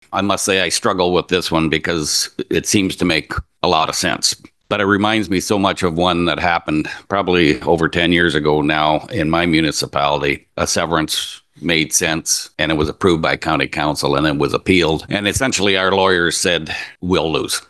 Central Huron Mayor Jim Ginn said that he unfortunately had learned this lesson the hard way in the past.